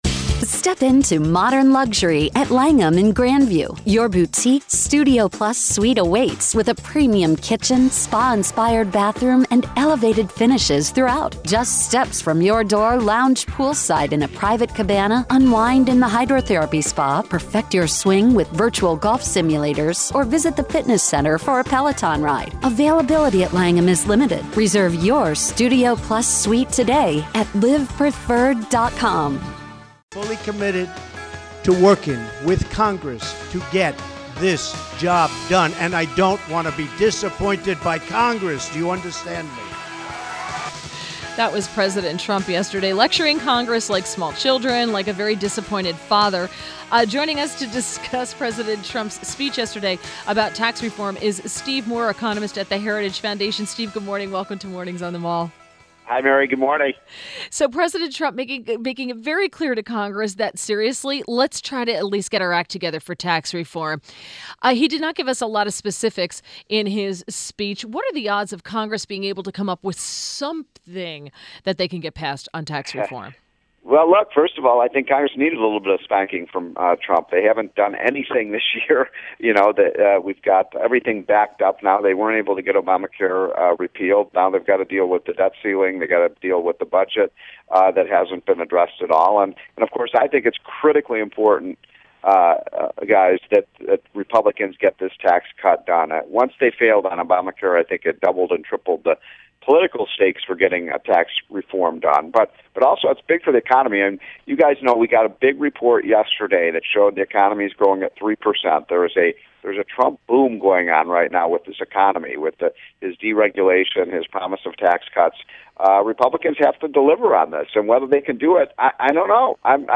INTERVIEW -- STEVE MOORE - ECONOMIST at THE HERITAGE FOUNDATION